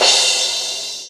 074 - Crash-2.wav